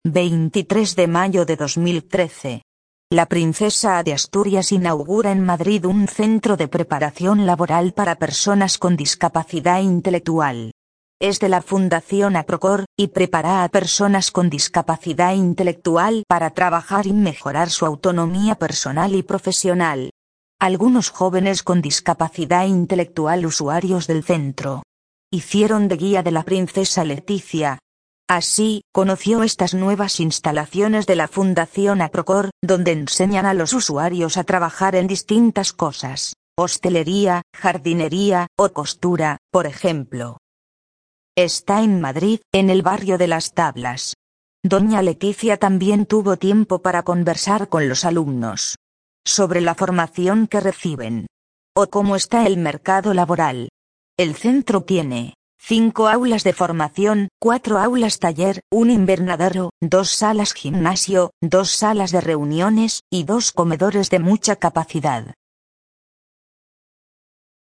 Audio noticia en léctura fácil - la Princesa de Asturias inaugura en Madrid un centro de preparación laboral para personas con discapacidad inteletual